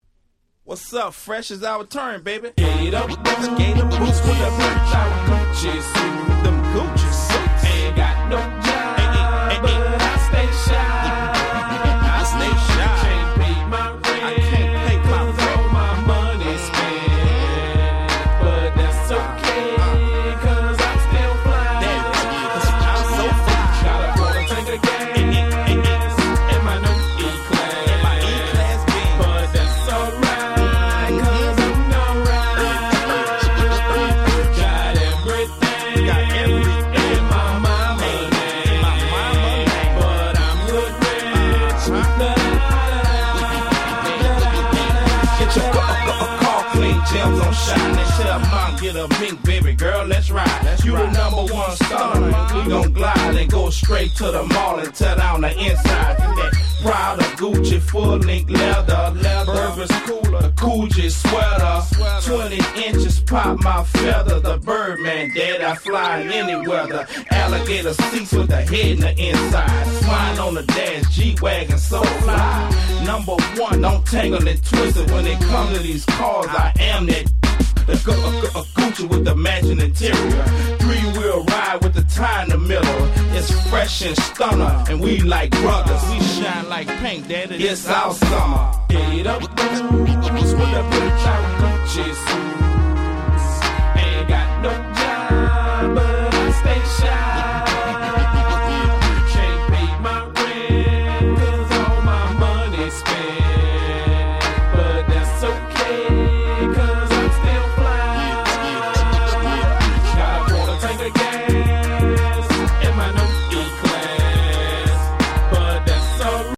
02' Super Hit Hip Hop !!
下手ウマなサビが最強に格好良い！！
問答無用の00's Southern Hip Hop Classicsです！